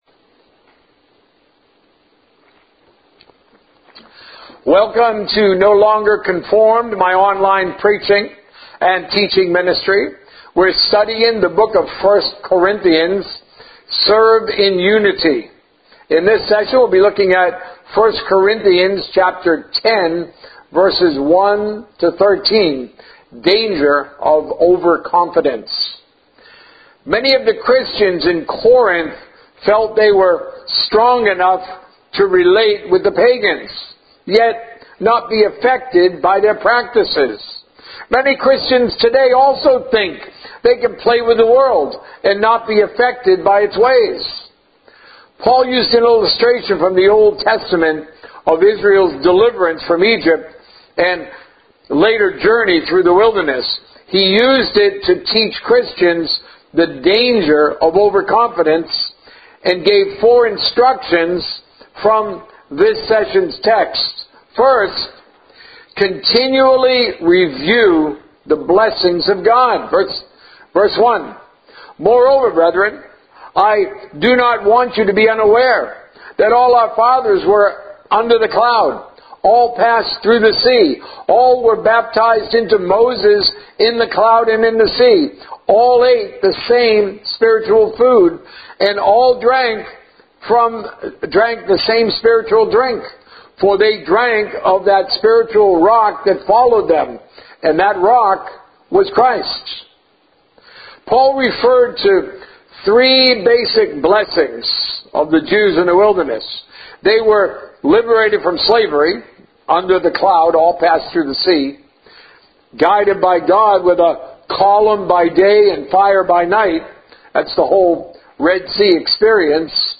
A message from the series "Trusting God."